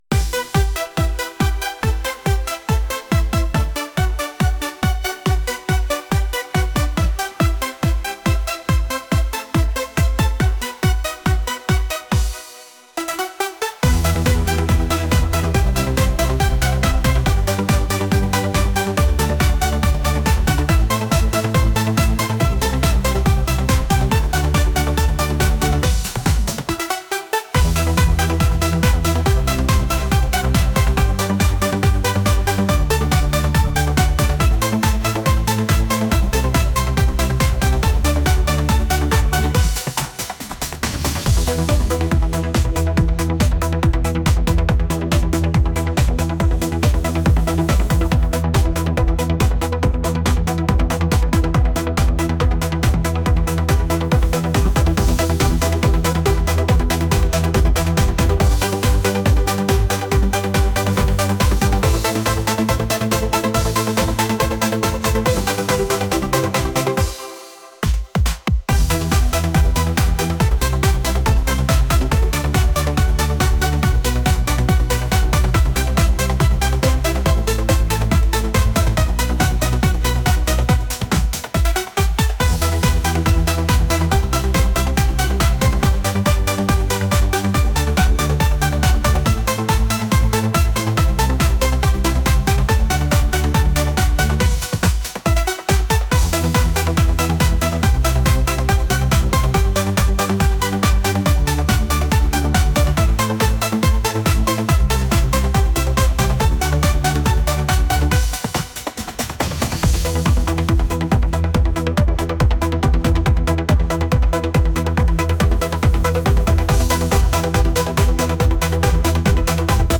pop | electronic